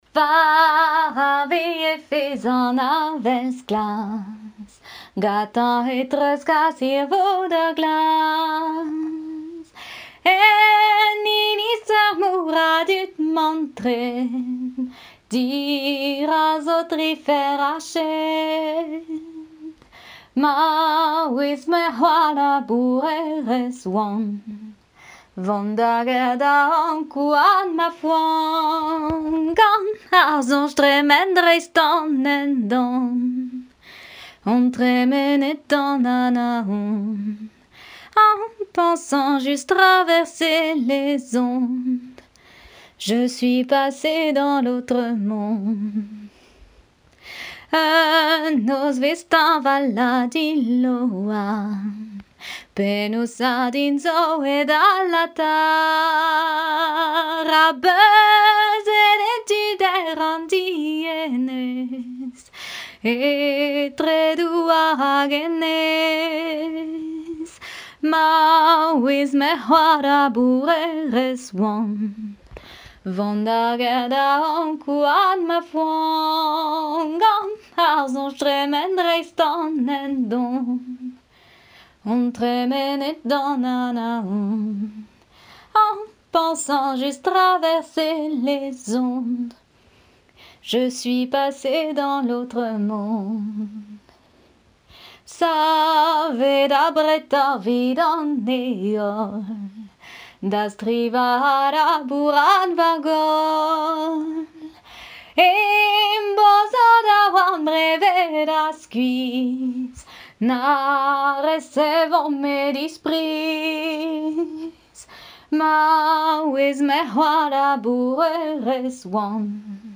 Genre strophique
Compositeur Catherine Boissé
Catégorie Pièce musicale inédite